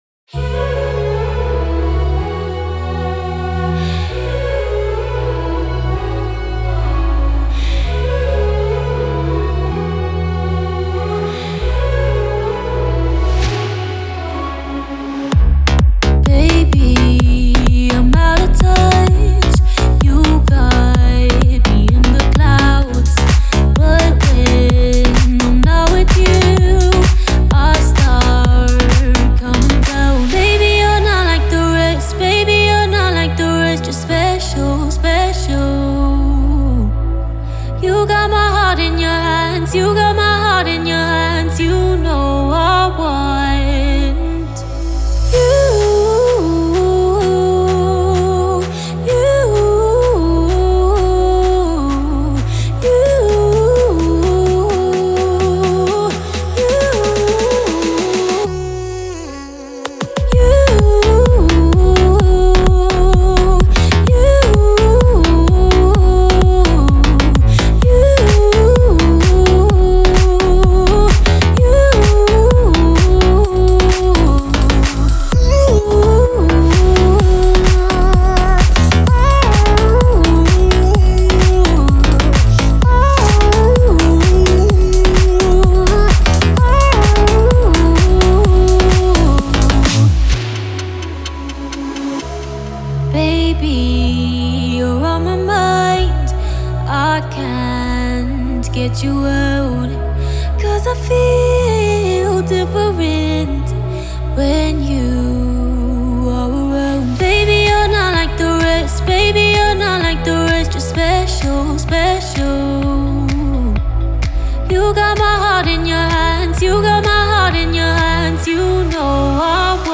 это трек в жанре инди-поп